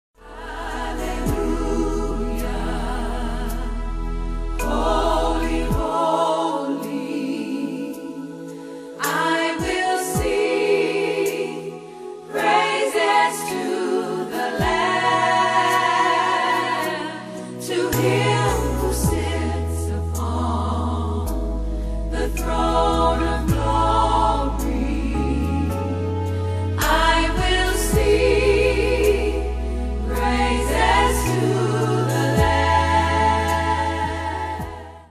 an anthem of worship.